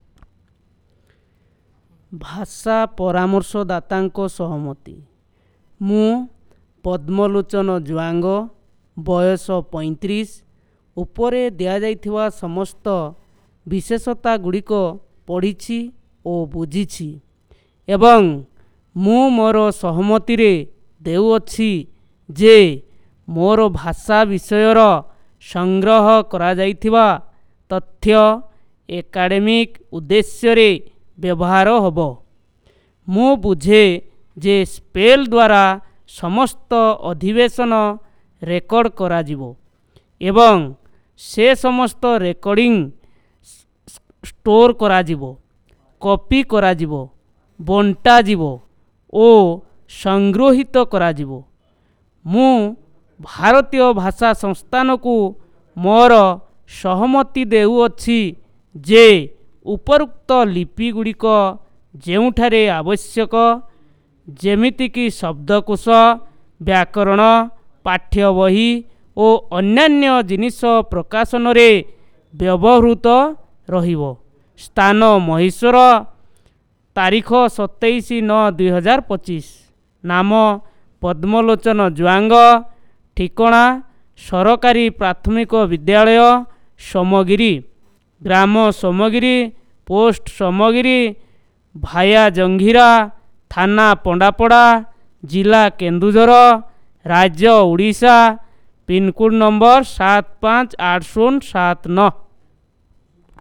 NotesThis is an elicitation of profile of informant with his consent for documenting the language by SPPEL